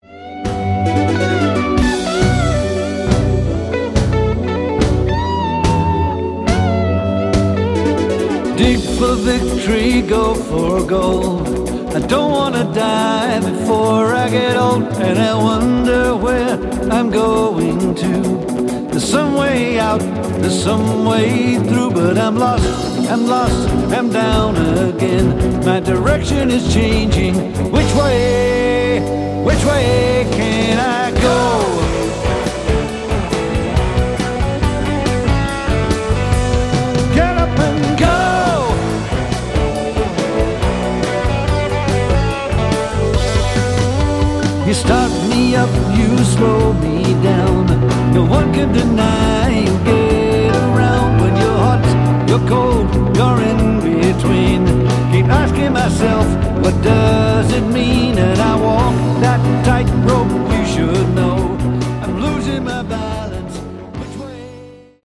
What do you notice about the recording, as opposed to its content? Recorded May 4, 2010 in Basel, Switzerland. live